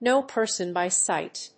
アクセントknów a person by síght